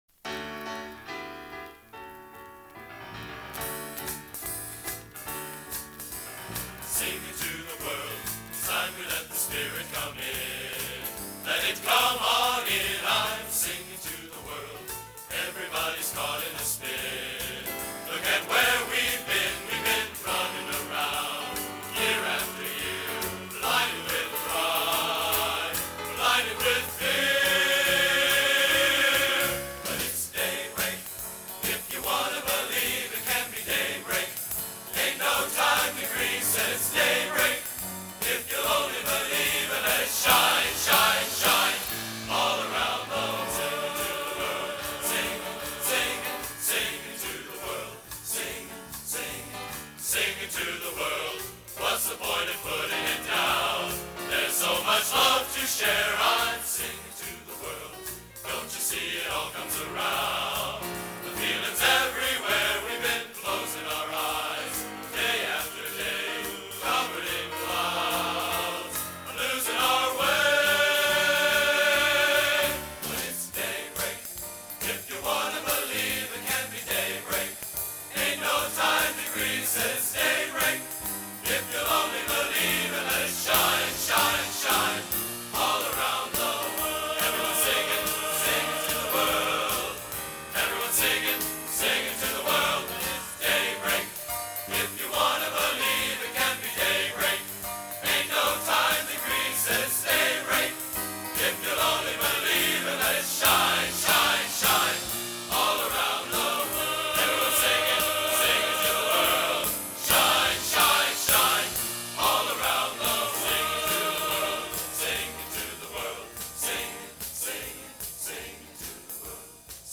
Location: West Lafayette, Indiana
Genre: | Type: Studio Recording